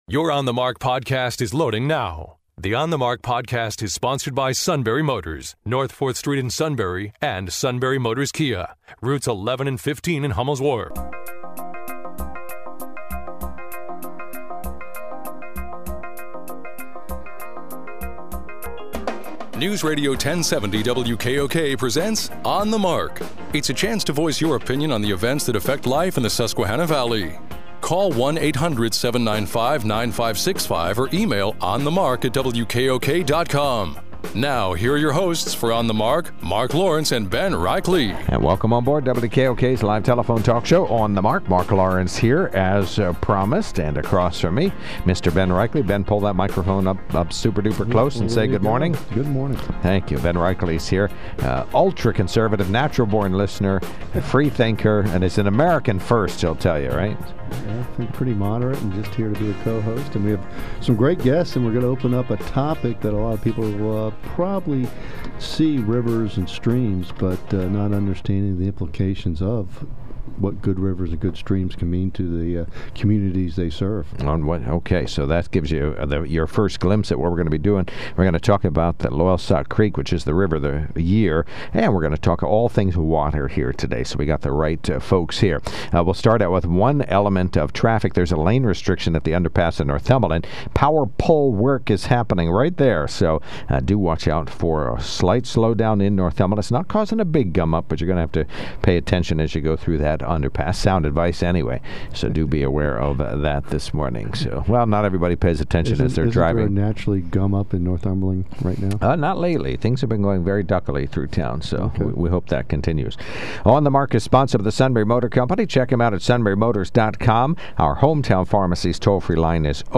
Discussion of River of the Year Designation on WKOK radio